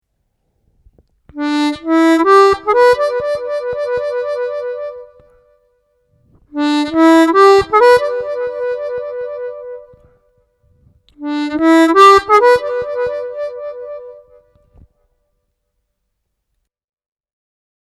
Сыграть музыкальную фразу с трелью -1 +2 -2 -(34)